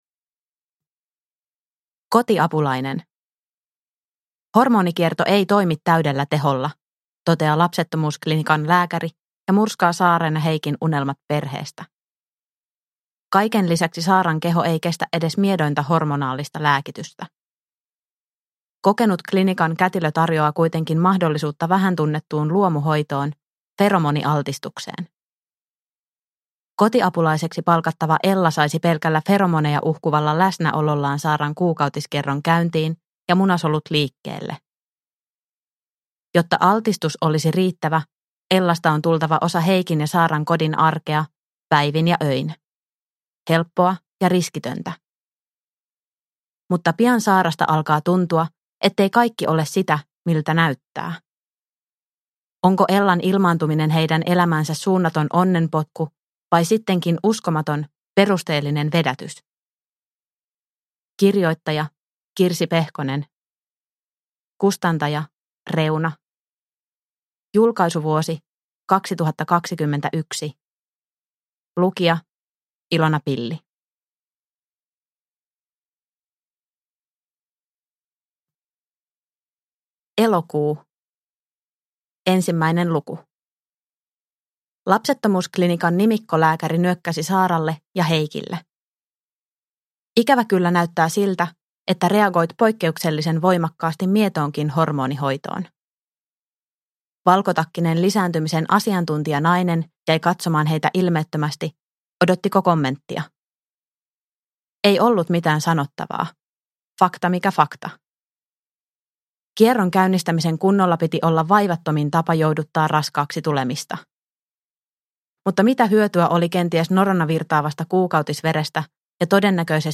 Kotiapulainen – Ljudbok – Laddas ner